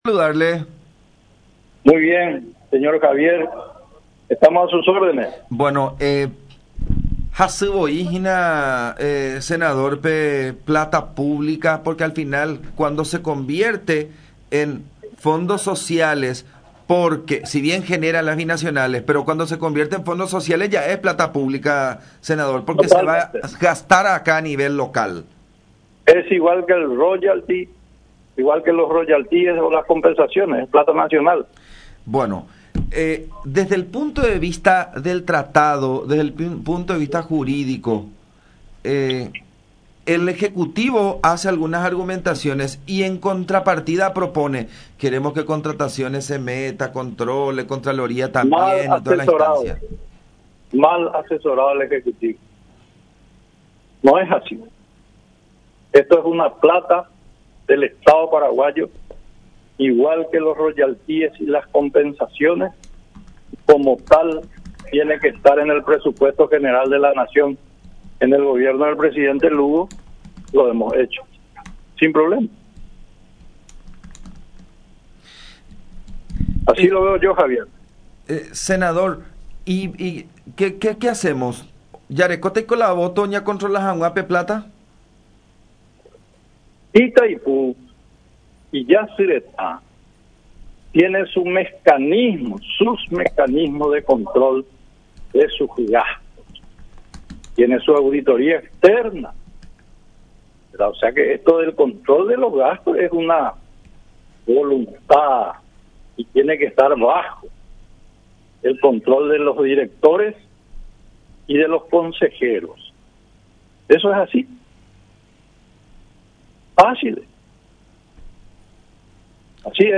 “El gobierno actual está mal asesorado con relación a estos fondos sociales de las binacionales. Es igual a los royalties. Es plata nacional, plata del Estado paraguayo”, expuso Rodríguez en conversación con La Unión.